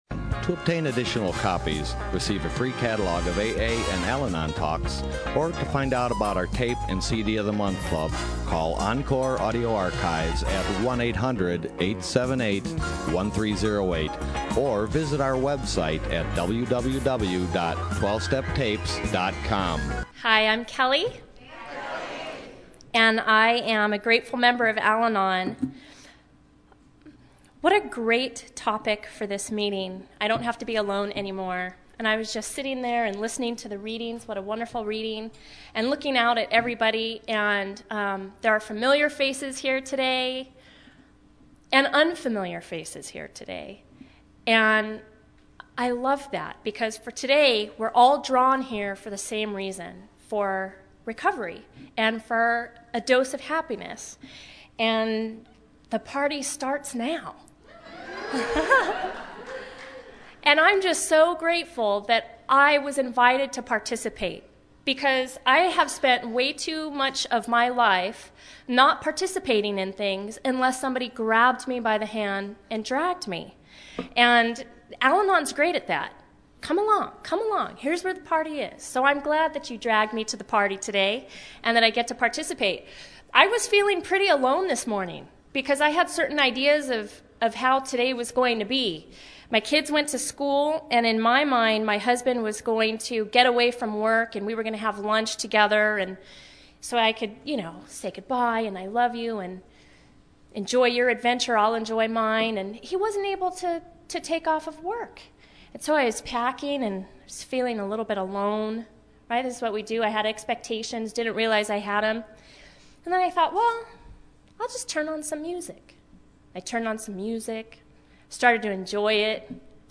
Orange County AA Convention 2015